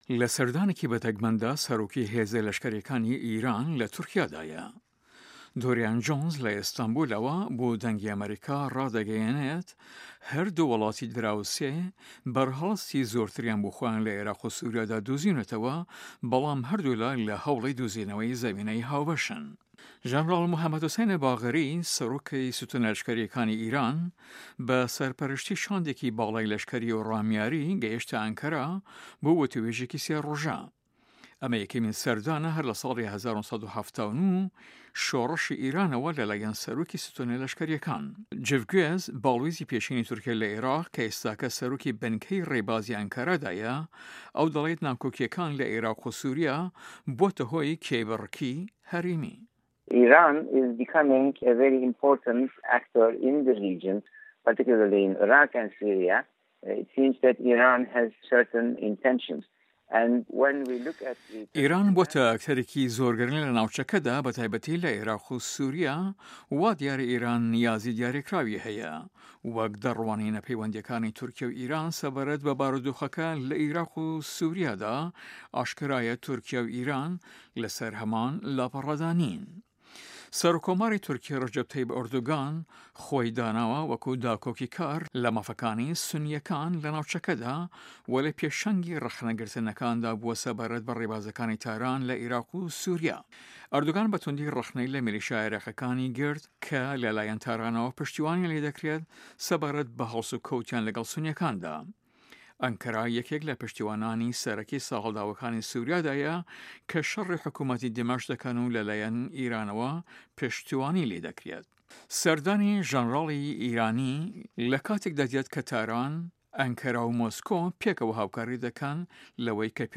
راپۆرتی تورکیا و ئێران